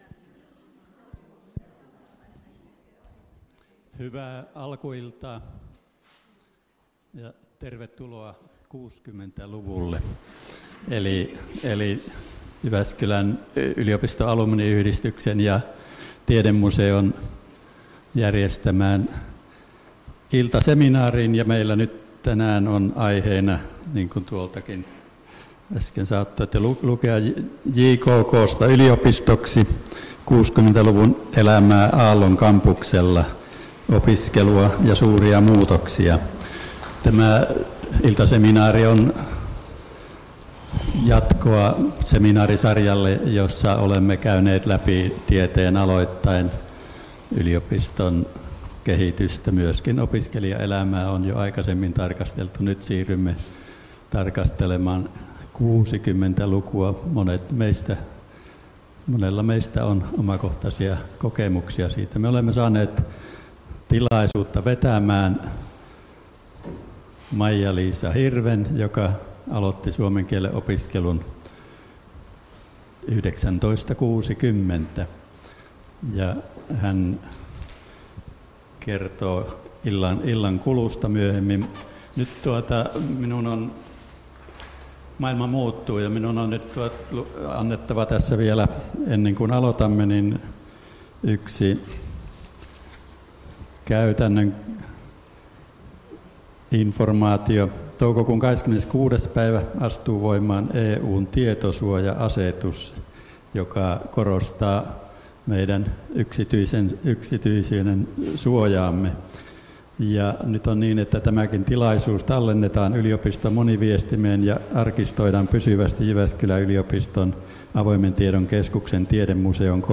Jykysin seminaari esittelee 1960-luvun elämää Aallon kampuksella, opiskelua ja suuria muutoksia.